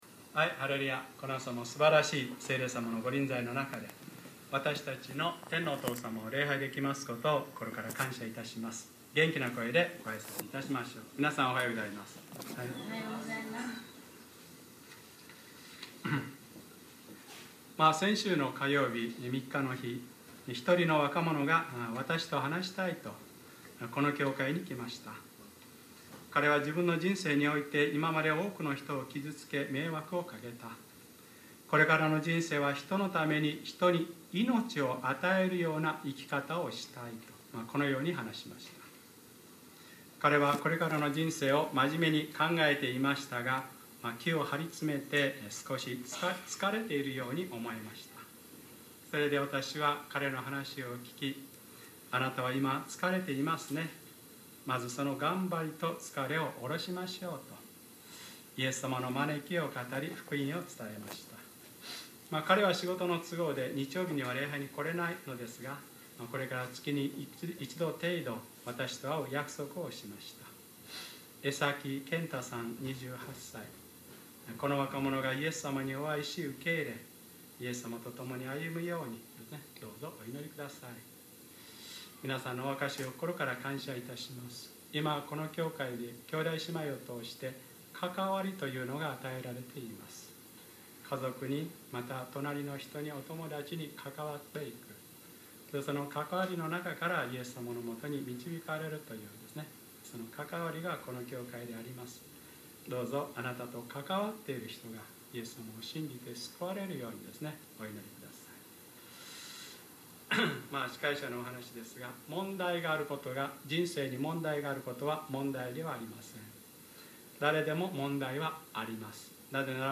2012年7月8日(日）礼拝説教 『ルカ10/ ルカ4章14節～』